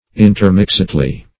intermixedly - definition of intermixedly - synonyms, pronunciation, spelling from Free Dictionary Search Result for " intermixedly" : The Collaborative International Dictionary of English v.0.48: Intermixedly \In`ter*mix"ed*ly\, adv. In a mixed manner.